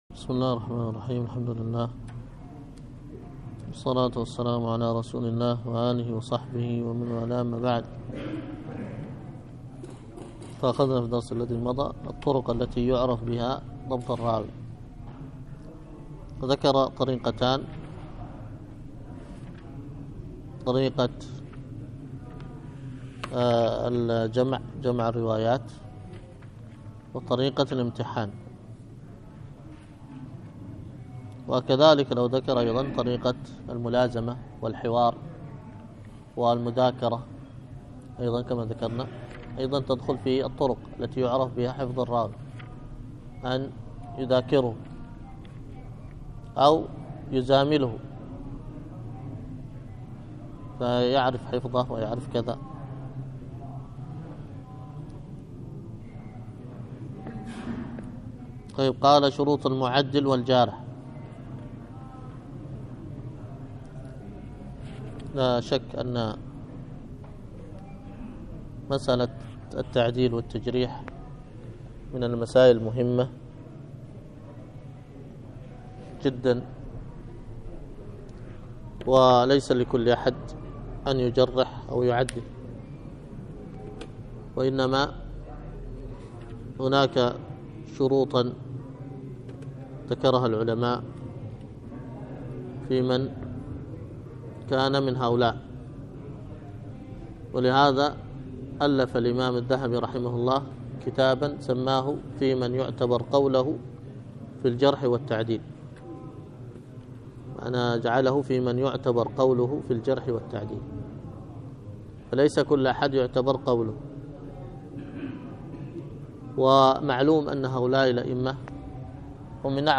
الدرس في التعليقات على كتاب الأدب المفرد 249، ألقاها